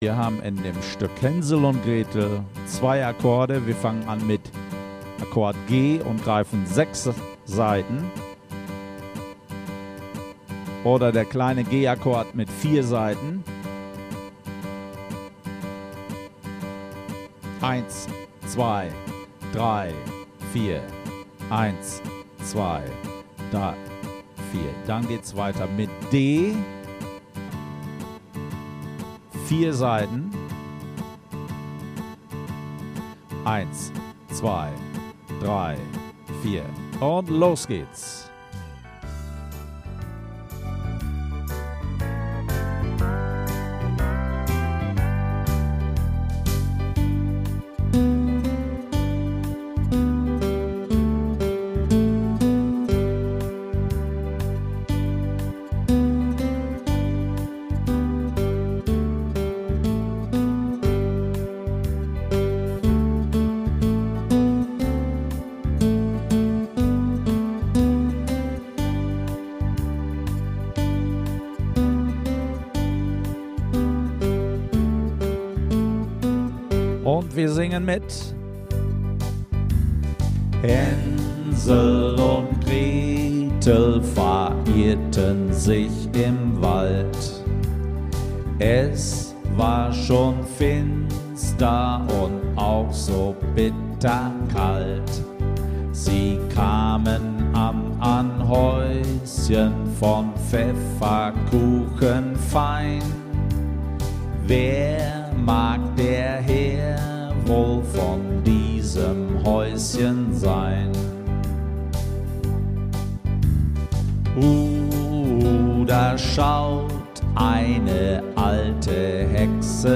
Wir versuchen 2 Akkorde zu wechseln